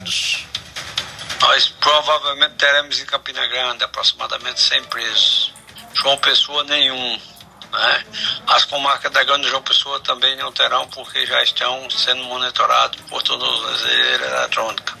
“Provavelmente teremos em Campina Grande aproximadamente 100 presos. Em João Pessoa, nenhum. As comarcas de João Pessoa também não terão porque já estão sendo monitorados por tornozeleira”, explicou durante entrevista ao programa Arapuan Verdade, da Rádio Arapuan FM desta quarta-feira (18/12).